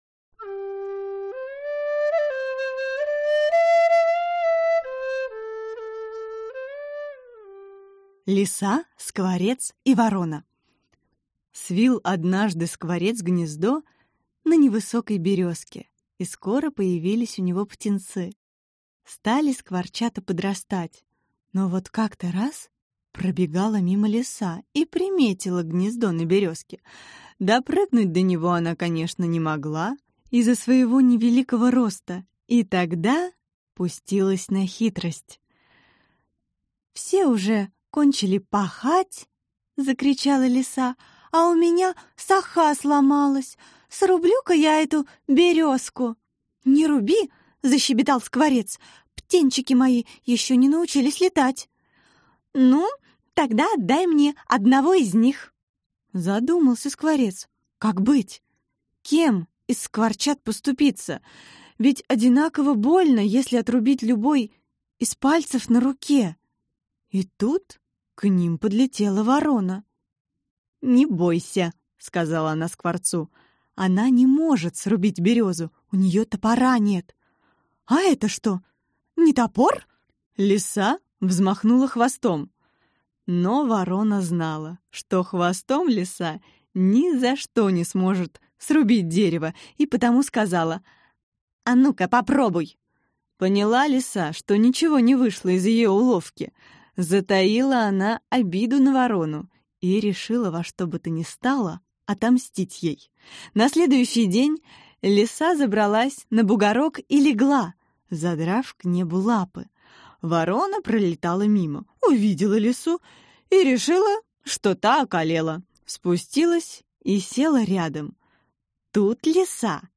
Аудиокнига Казахские сказки и притчи | Библиотека аудиокниг